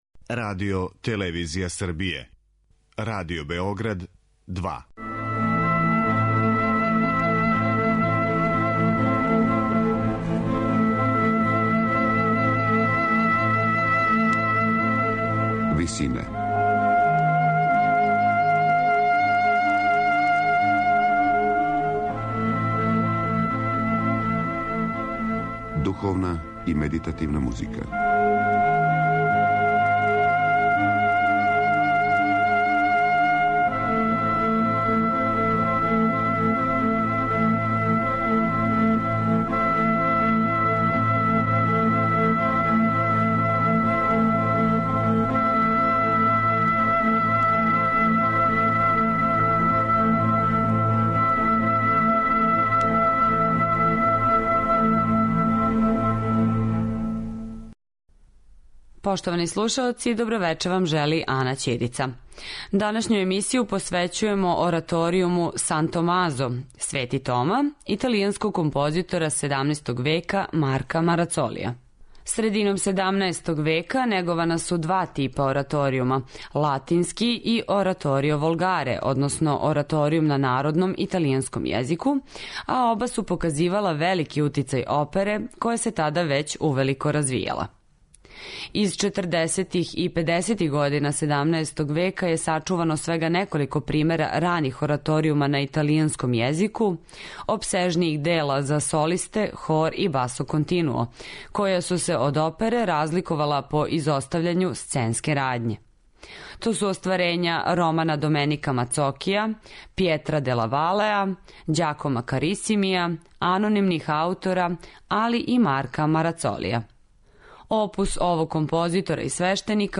Ораторијум 'Свети Тома', Марка Марацолија
На крају програма, у ВИСИНАМА представљамо медитативне и духовне композиције аутора свих конфесија и епоха.